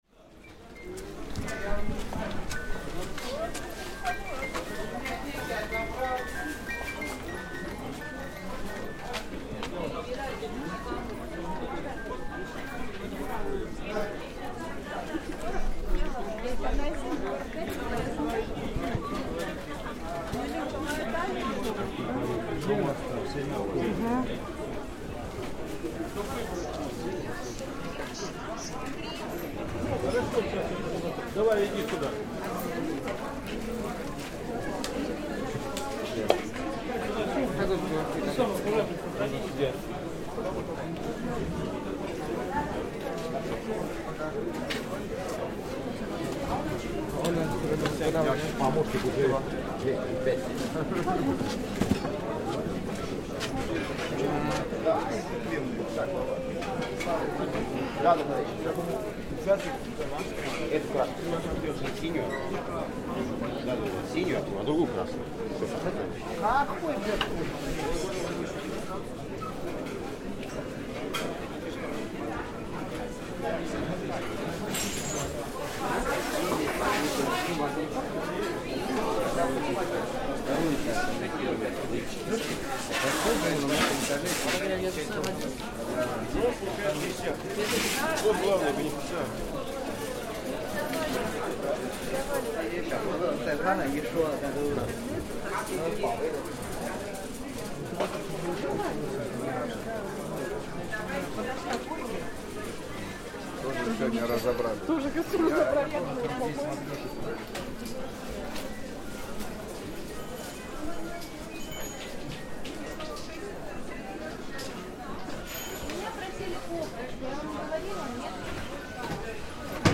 A walkthrough of the famous (if somewhat run-down) Ismailovo market in Moscow, where you can buy anything your heart desires, as long as what your heart desires is a Matryoshka doll or a piece of Soviet memorabilia.